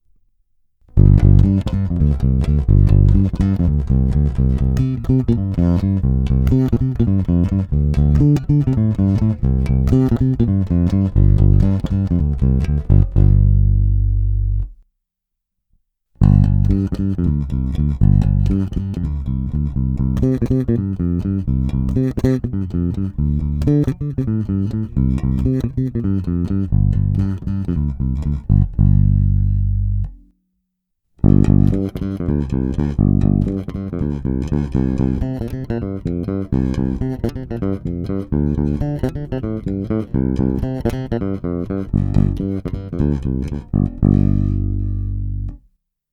Stejná bonusová ukázka s použitím EQ, basy na aktivní elektronice dané skoro naplno a výšky skoro úplně stažené